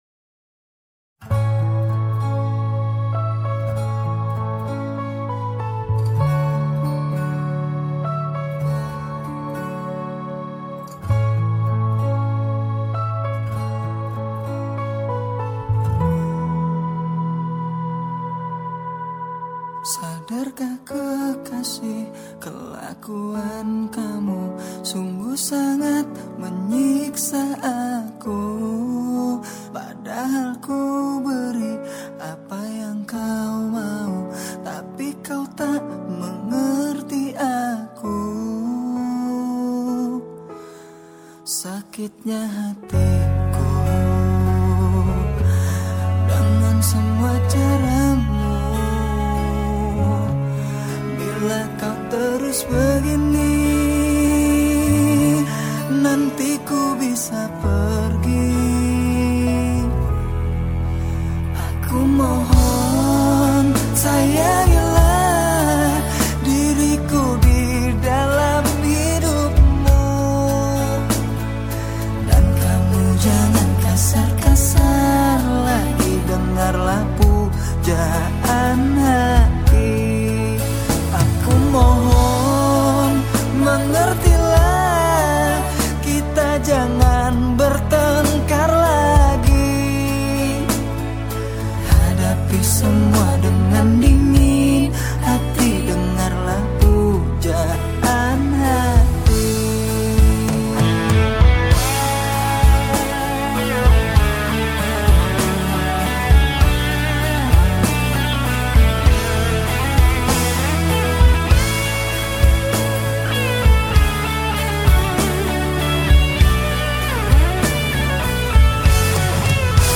MELAYU
POP